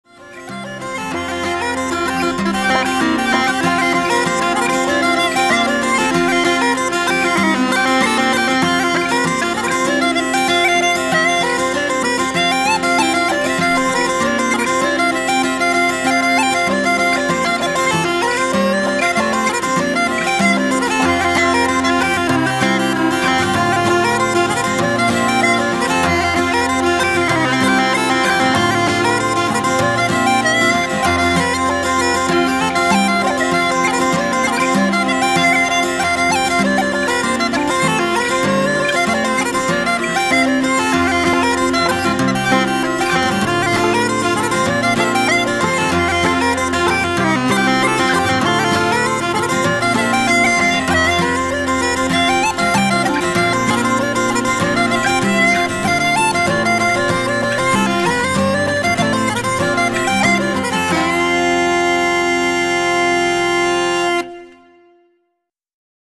Irish pipe setting above uses a lot of C# notes to great effect.